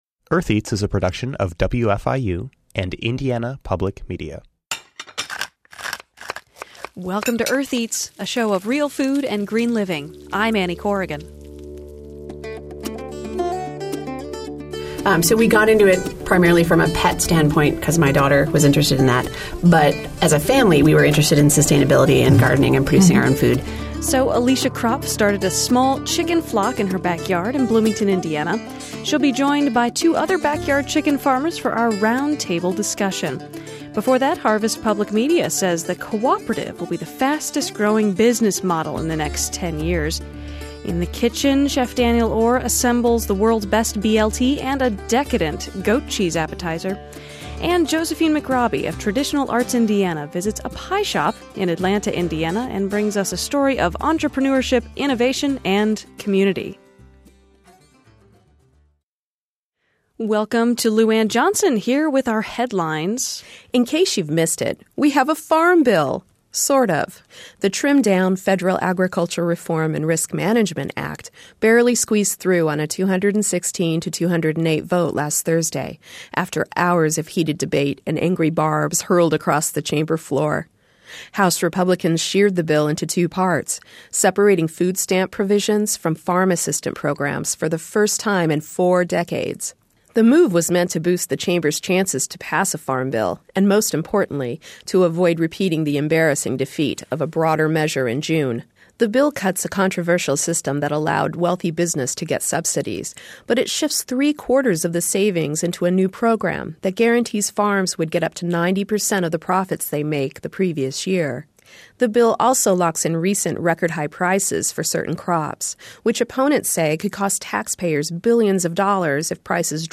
Three backyard chicken farmers talk about what it's like to raise a flock.
She will be joined by two other backyard chicken farmers for our roundtable discussion.